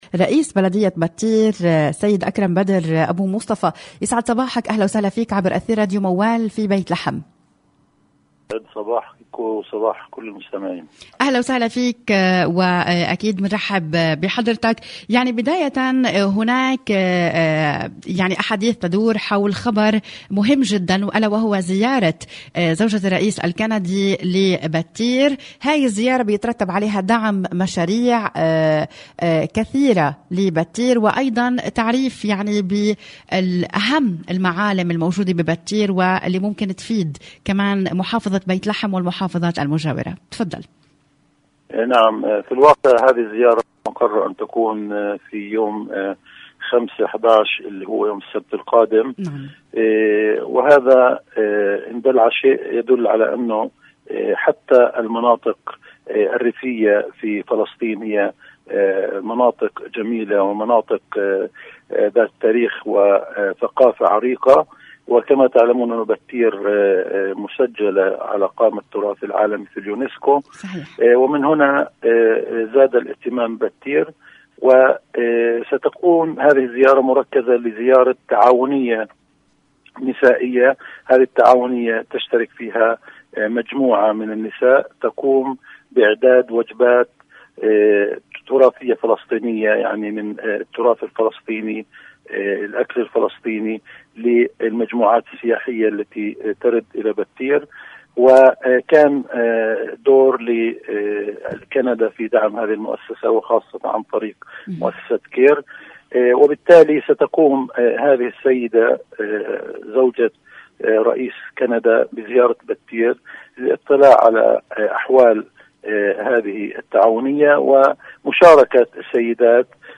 راديو موال-تحدث رئيس بلدية بتير السيد اكرم بدر لبرنامج”دواوين البلد” في راديو موال عن الزيارة التي ستقوم بها زوجة الرئيس الكندي يوم السبت لبدية بتير والتي يترتب عليها الكثير من الانجازات التي تضاف الى البلدية و منها انشاء و دعم مشاريع نسوية هامة.
المزيد في هذا اللقاء بالصوت/اكرم بدر: